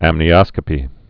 (ămnē-ŏskə-pē)